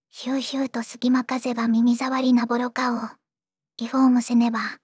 voicevox-voice-corpus / ROHAN-corpus /ずんだもん_ヒソヒソ /ROHAN4600_0008.wav